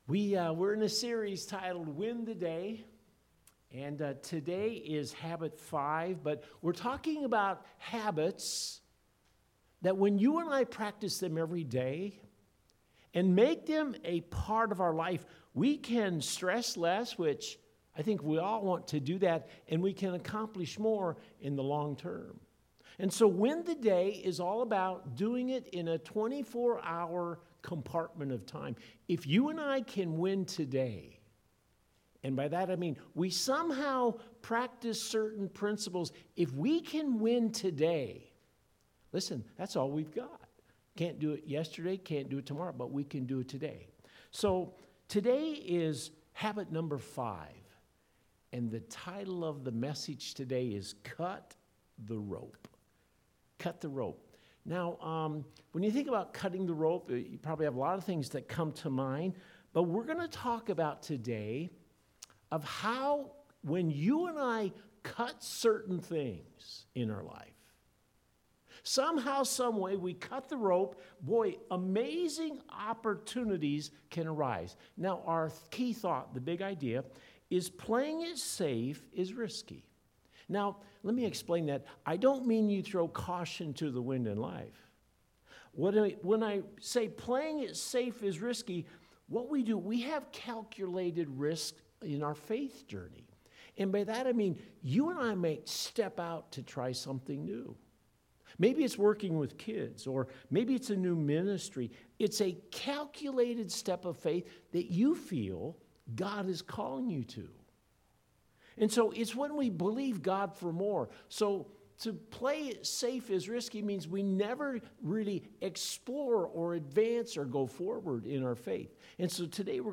5-2-21-Cut-the-Rope-Early-Service-and-Communion.mp3